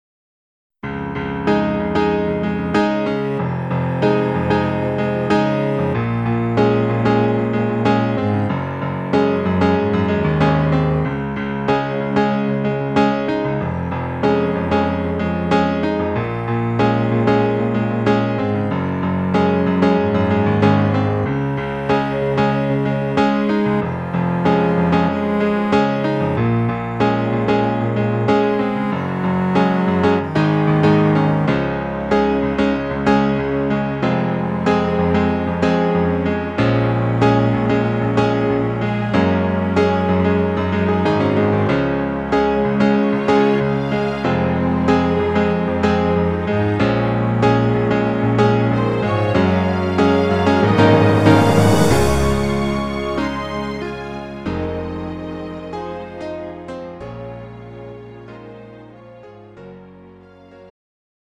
음정 -1키
장르 가요 구분 Lite MR
Lite MR은 저렴한 가격에 간단한 연습이나 취미용으로 활용할 수 있는 가벼운 반주입니다.